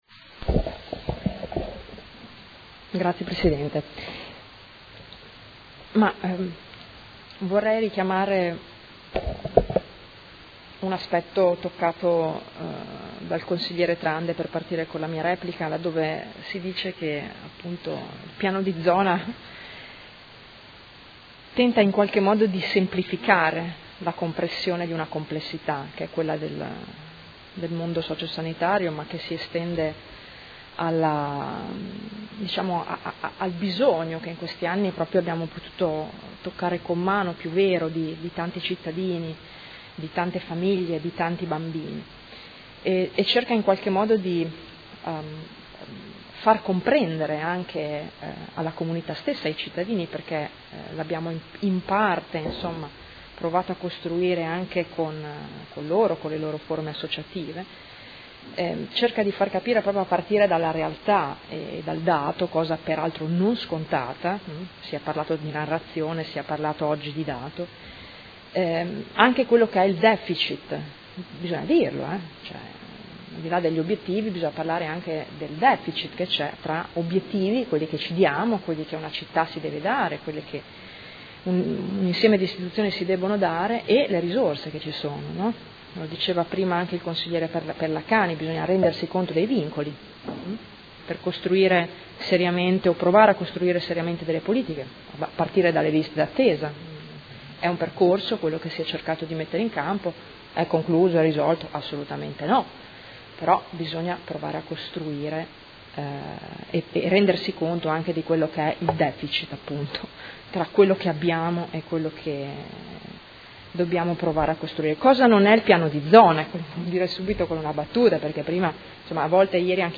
Seduta del 12/07/2018. Replica a dibattito su proposta di deliberazione: Approvazione dell’Accordo di Programma fra Comune di Modena ed Azienda USL di Modena avente ad oggetto il Piano di Zona Triennale 2018-2010 per la Salute e il Benessere sociale
Audio Consiglio Comunale